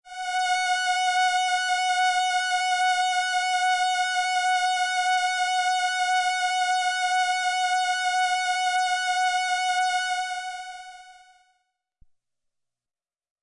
标签： MIDI-速度-32 F6 MIDI音符-90 罗兰-JX-3P 合成器 单票据 多重采样
声道立体声